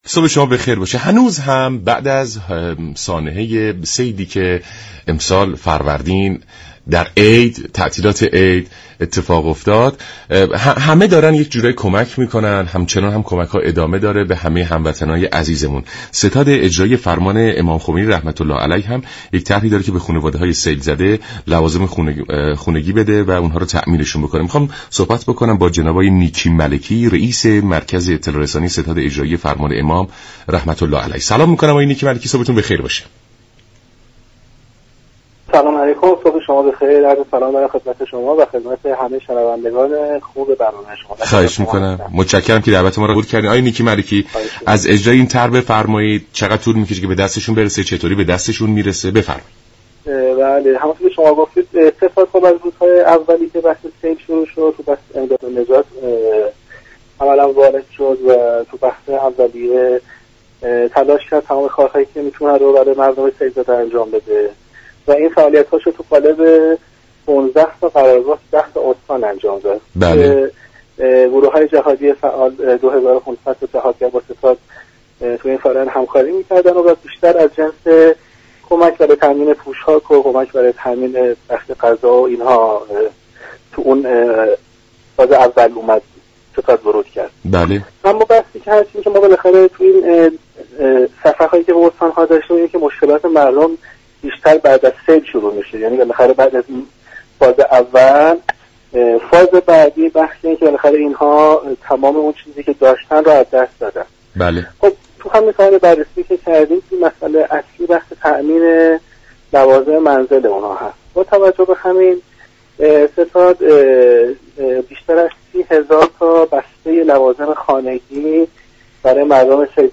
گفت و گو با برنامه سلام صبح بخیر رادیو ایران